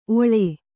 ♪ oḷe